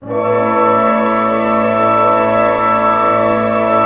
Index of /90_sSampleCDs/Roland LCDP08 Symphony Orchestra/ORC_ChordCluster/ORC_Pentatonic